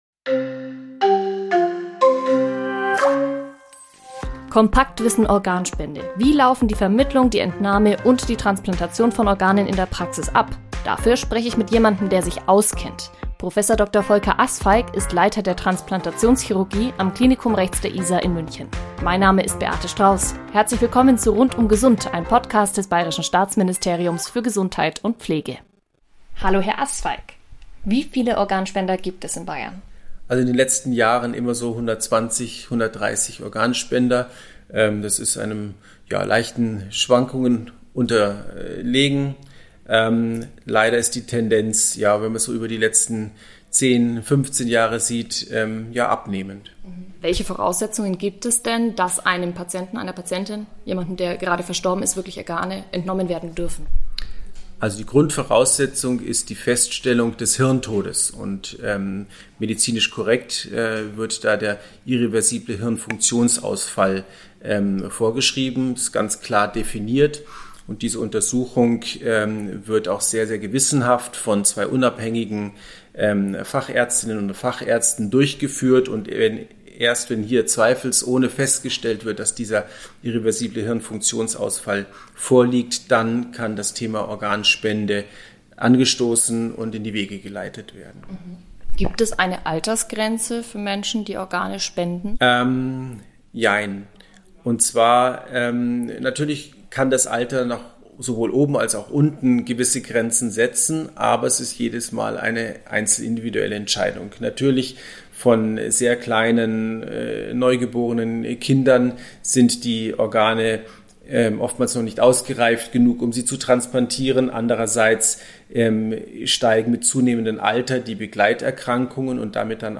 Heute beantwortet er uns unsere Fragen.